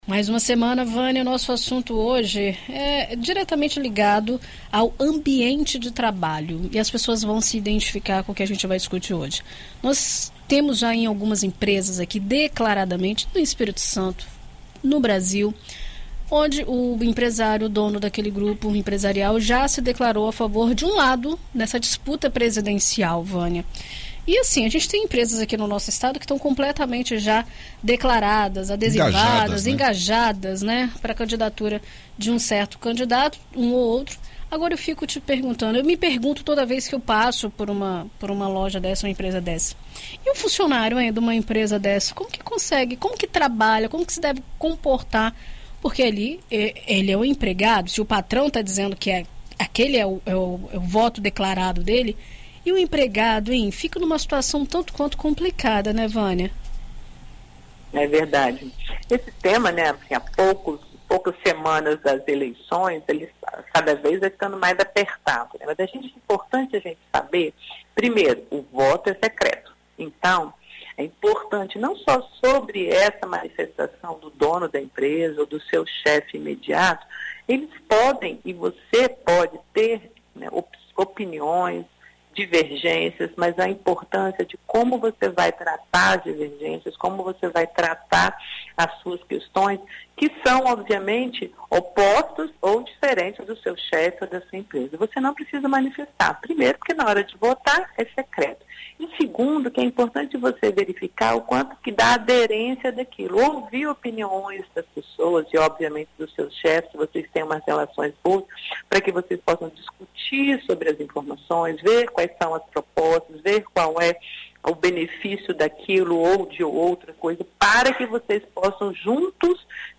Na coluna Vida e Carreira desta segunda-feira (19), na BandNews FM Espírito Santo